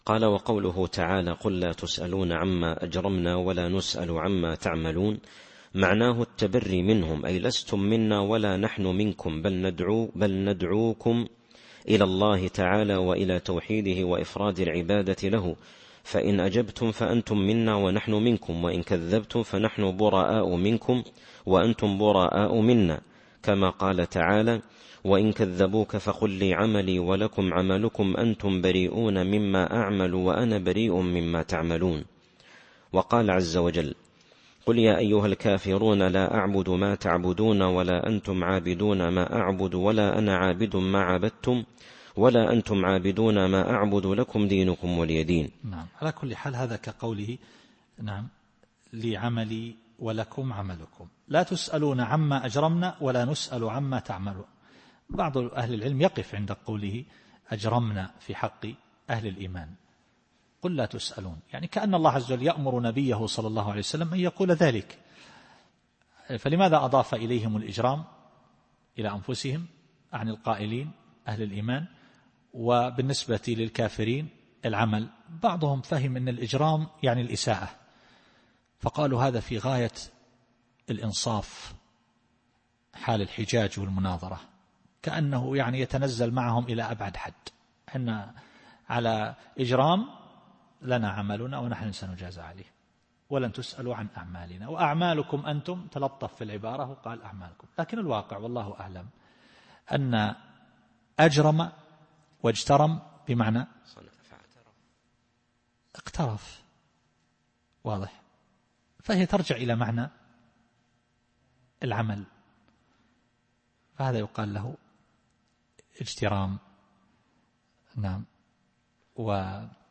التفسير الصوتي [سبأ / 25]